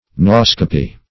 Search Result for " nauscopy" : The Collaborative International Dictionary of English v.0.48: Nauscopy \Naus"co*py\, n. [Gr. nay^s ship + -scopy: cf. F. nauscopie.]